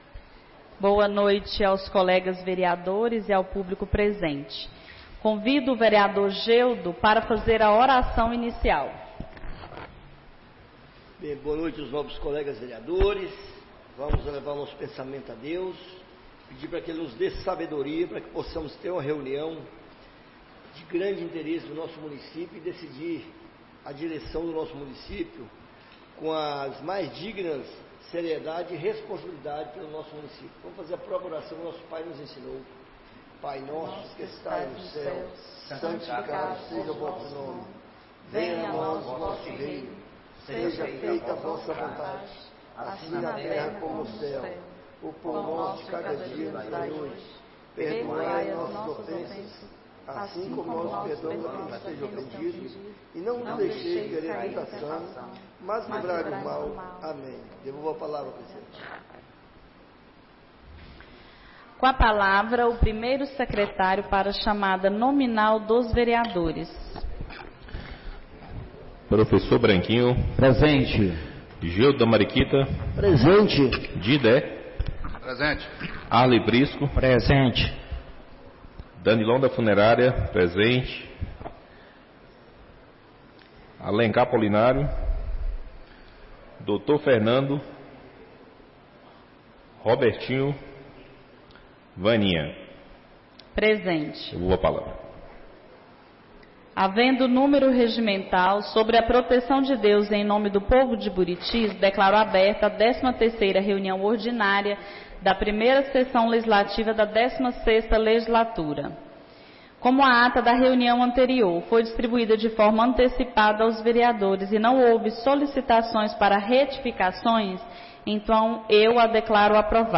13ª Reunião Ordinária da 1ª Sessão Legislativa da 16ª Legislatura - 14-04-25